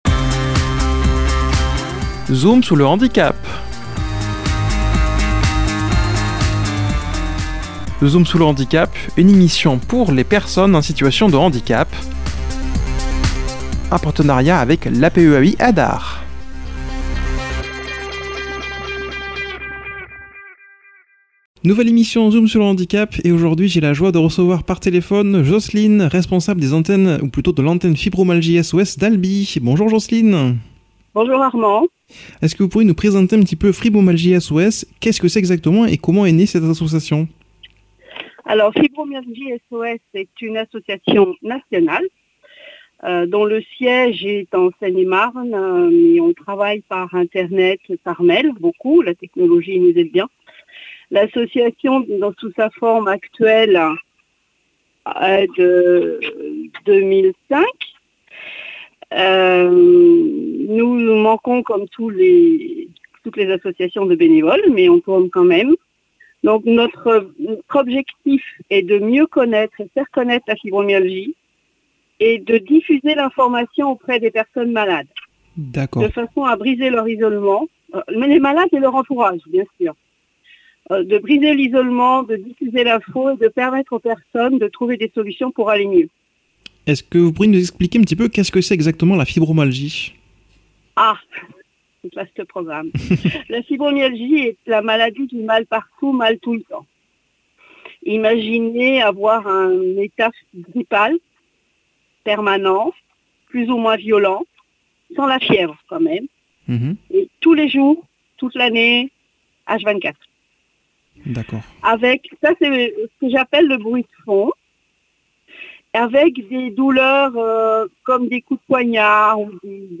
[ Rediffusion ]